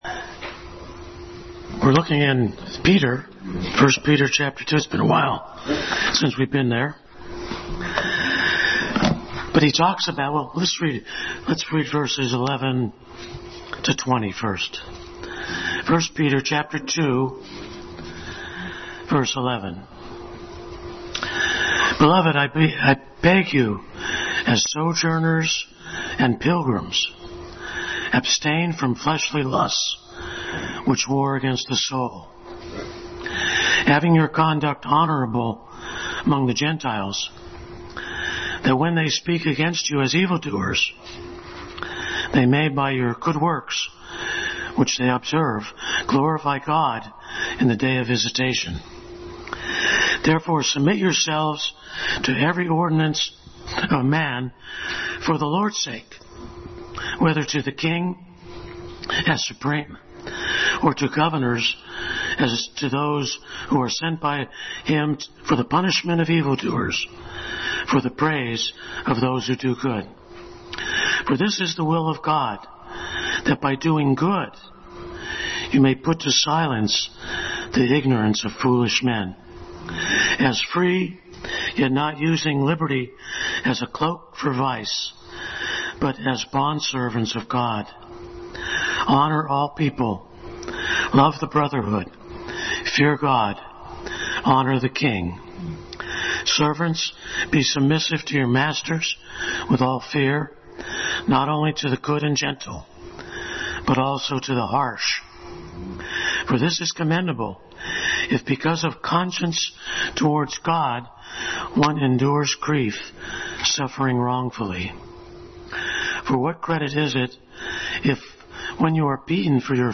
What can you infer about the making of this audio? Passage: 1 Peter 2:11-20 Service Type: Sunday School